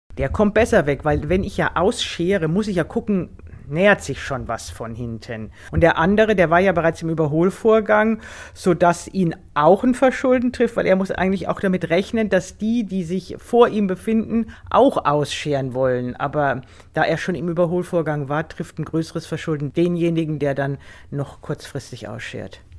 O-Ton + Magazin: Kolonne überholt – wer muss den Unfallschaden bezahlen?